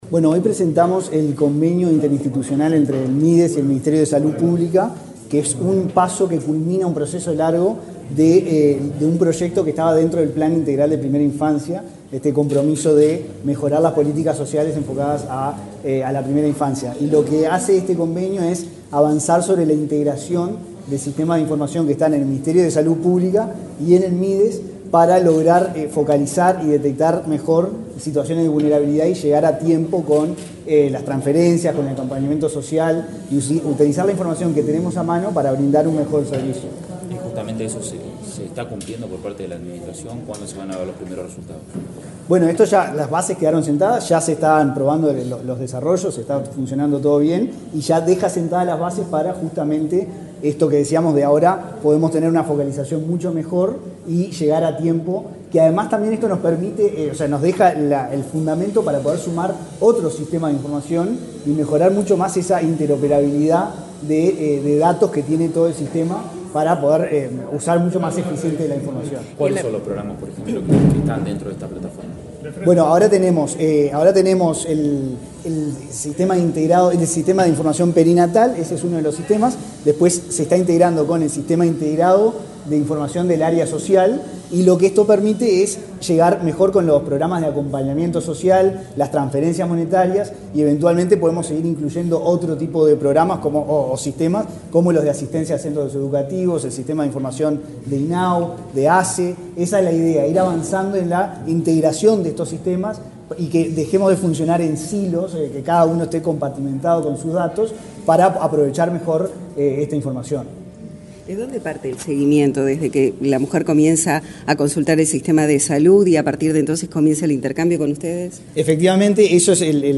Palabras del director nacional de Transferencias y Análisis de Datos del Mides, Antonio Manzi